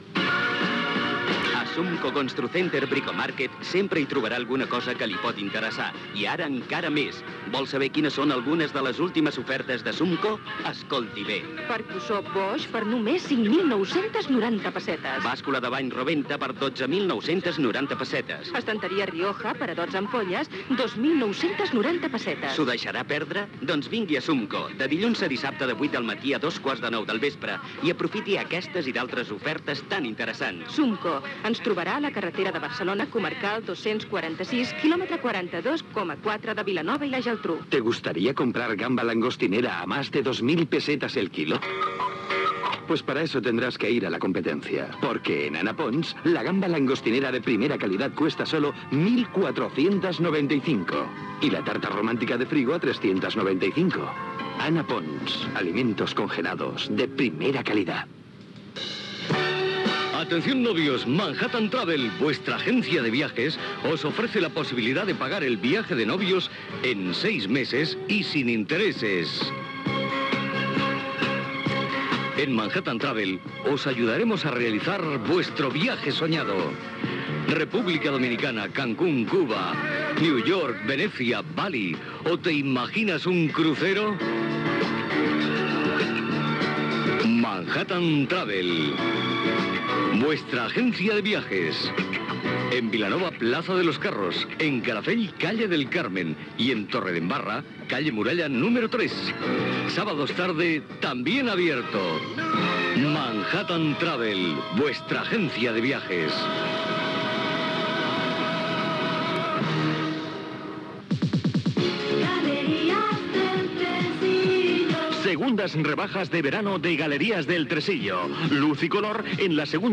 Publicitat, indicatiu de l'emissora
FM